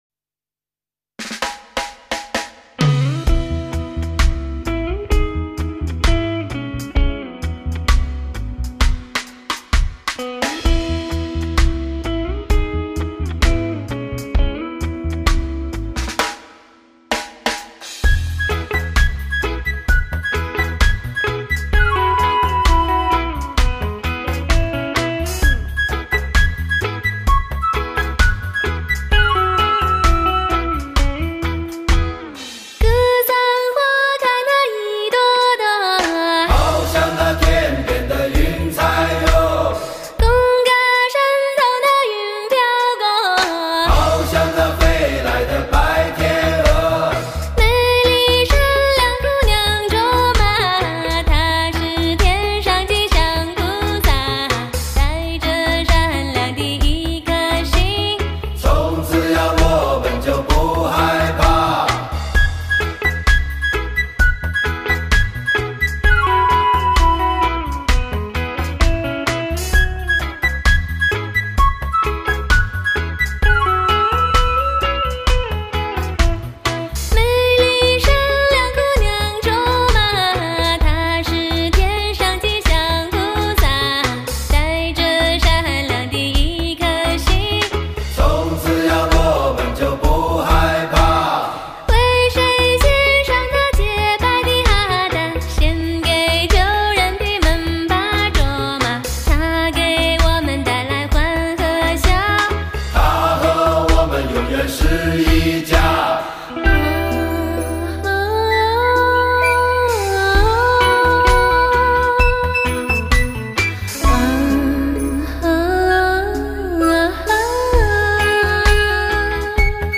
类型: 天籁人声